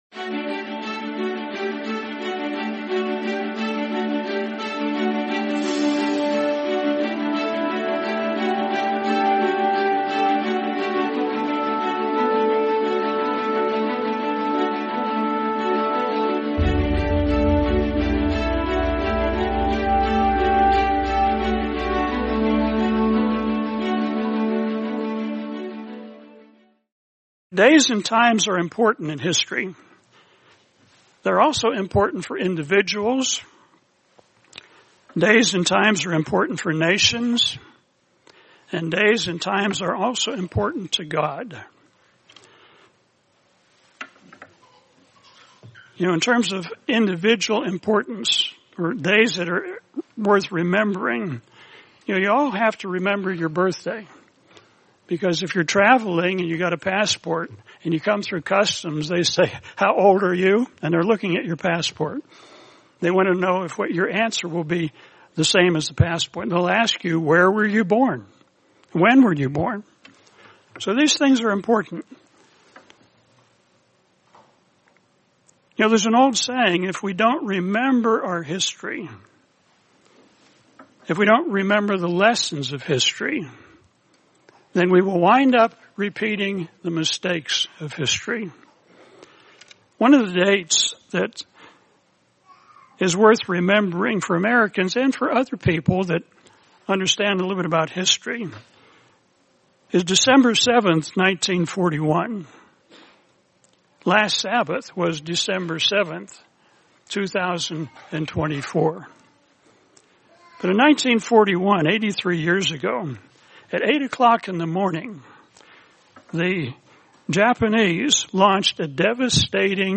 Sabbath: A Day to Remember | Sermon | LCG Members